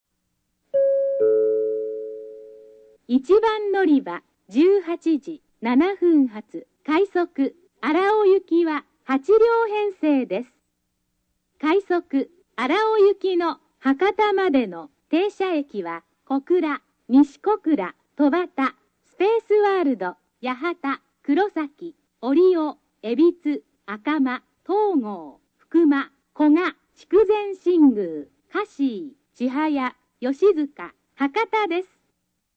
スピーカー：UNI-PEX・SC-10JA（ソノコラム・ミニ型）
音質：C
１番のりば 案内放送 快速・荒尾 (159KB/32秒)
音質は向上し快速の停車駅案内放送なども詳細で流れます。